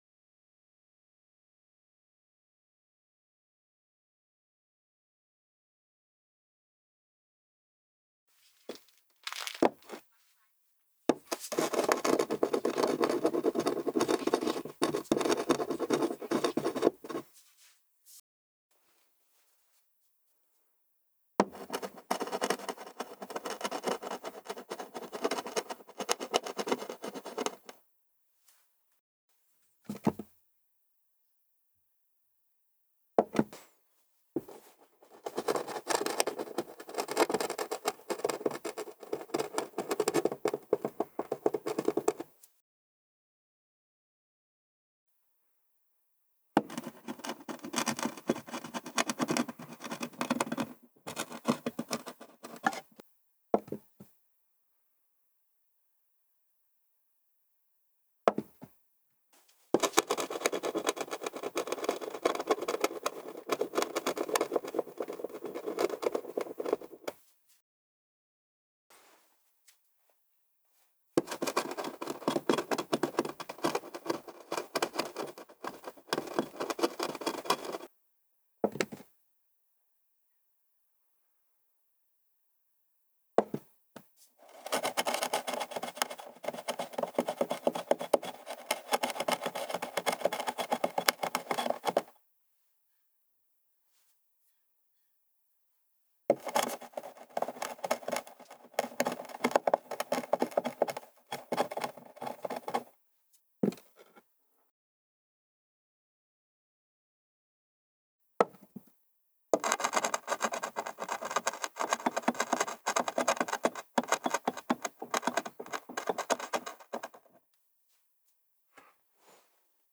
NPC_Drill_01.ogg